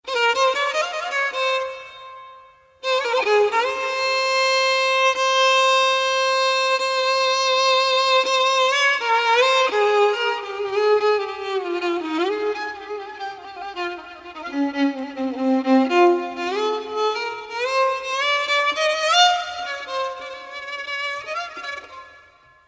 keman-sesi_24760.mp3